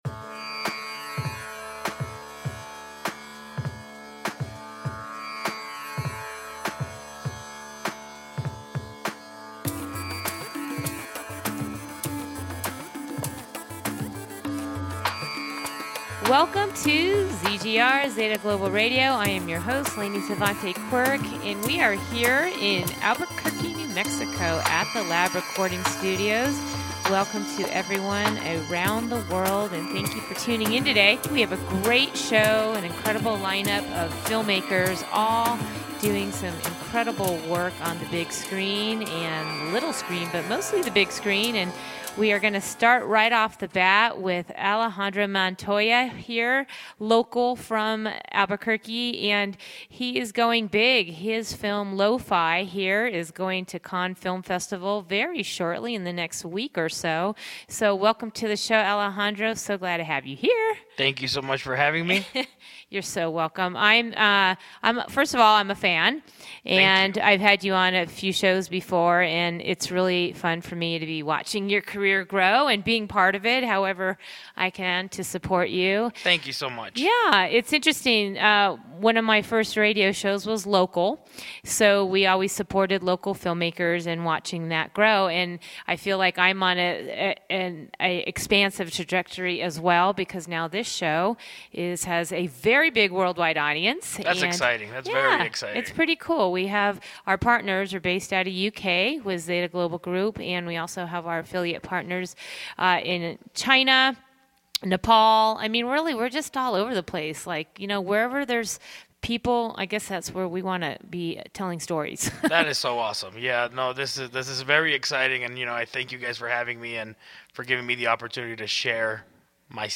Talk Show Episode